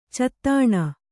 ♪ cattāṇa